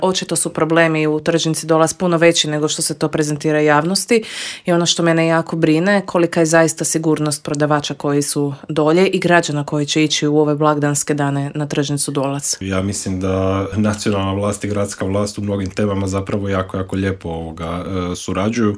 U intervjuu Media servisa ugostili smo jedne od glasnijih kritičara Tomislava Tomaševića i Možemo - nezavisnu zastupnicu u Skupštini Grada Zagreba Dinu Dogan i vijećnika u Vijeću Gradske četvrti Črnomerec Vedrana Jerkovića s kojima smo prošli kroz gradske teme.